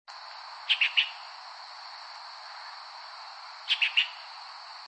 12-3墾丁烏頭翁叫1.mp3
烏頭翁 Pycnonotus taivanus
屏東縣 恆春鎮 墾丁青年活動中心
25-30 錄音環境 海岸林 發聲個體 行為描述 鳴叫 錄音器材 錄音: 廠牌 Denon Portable IC Recorder 型號 DN-F20R 收音: 廠牌 Sennheiser 型號 ME 67 標籤/關鍵字 鳥 備註說明 MP3檔案 12-3墾丁烏頭翁叫1.mp3